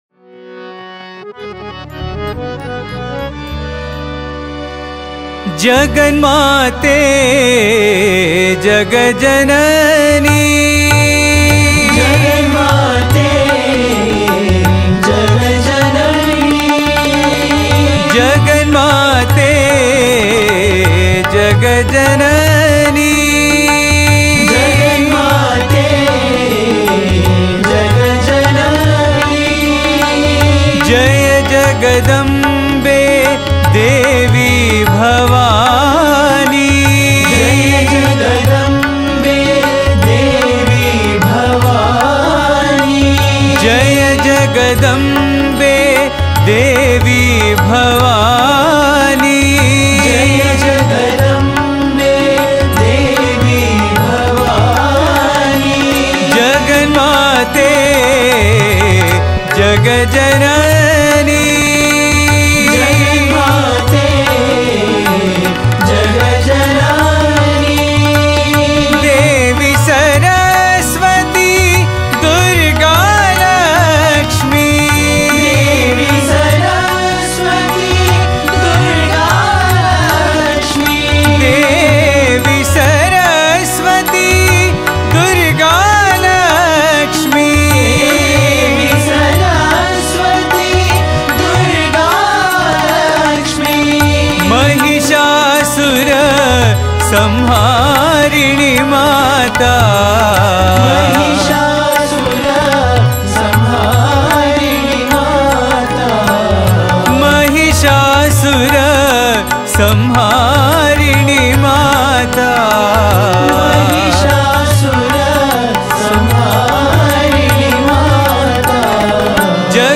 Author adminPosted on Categories Devi Bhajans